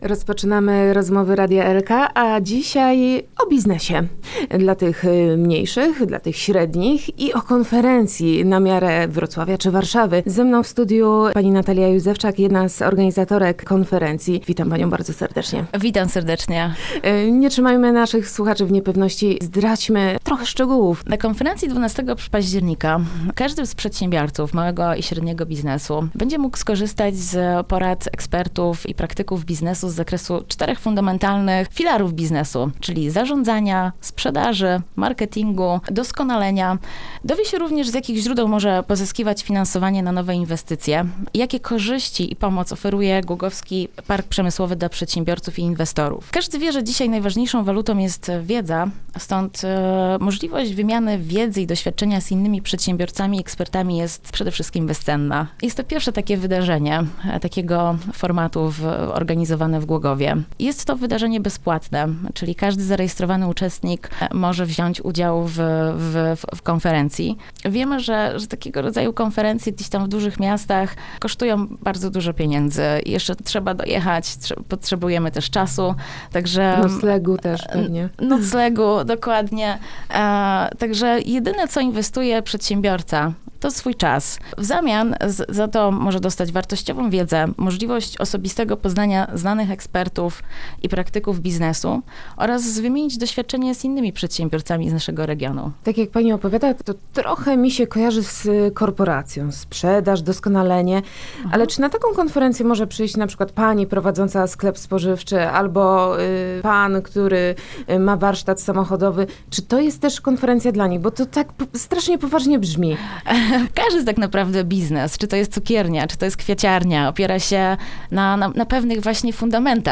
rozmowa_konferencja.mp3